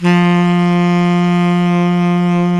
Index of /90_sSampleCDs/Roland L-CD702/VOL-2/SAX_Tenor V-sw/SAX_Tenor _ 2way
SAX TENORB07.wav